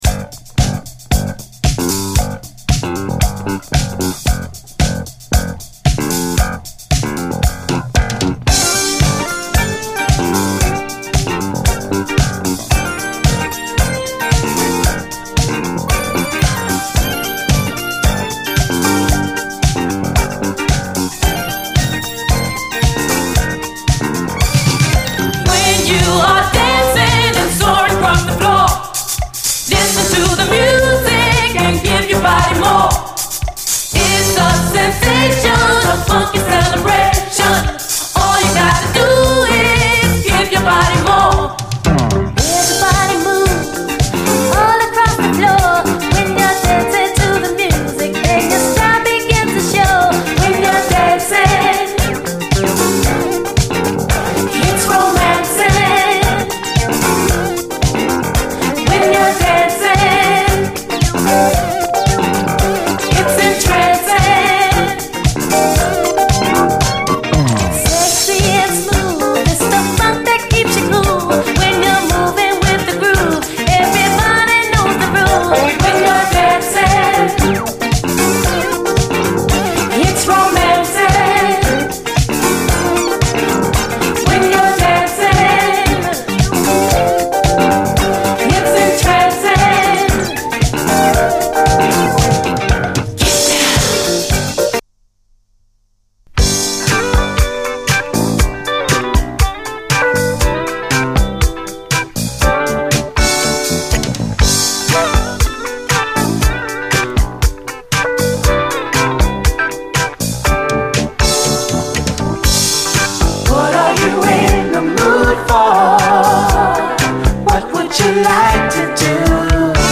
SOUL, 70's～ SOUL, DISCO
ガラージ的な雰囲気満点のファンキー・シンセ・ブギー
優しいメロディーの和み系ミディアム・ブギー・ソウル